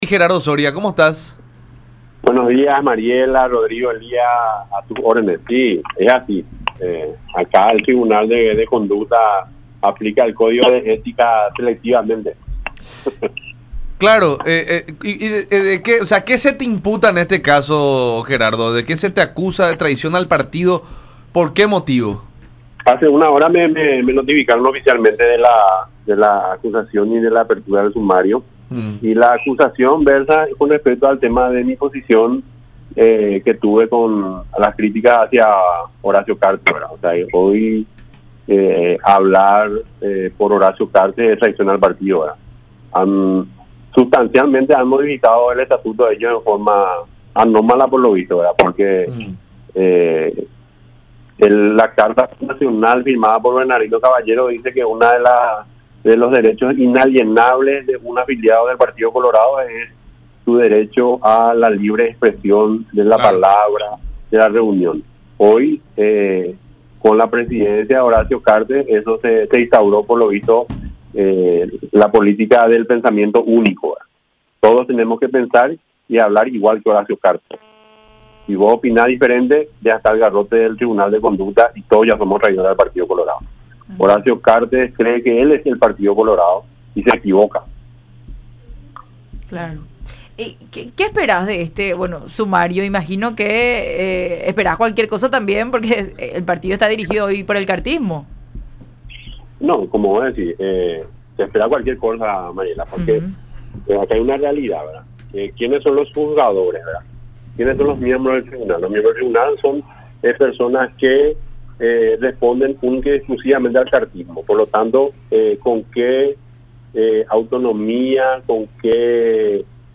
en diálogo con La Unión Hace La Fuerza a través de Unión TV y radio La Unión